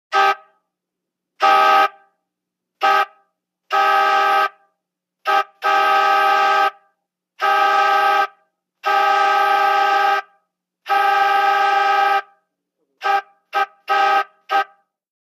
Antiques|Ext.|Horns | Sneak On The Lot
VEHICLES - HORNS ANTIQUE: Air horn, early, various toots.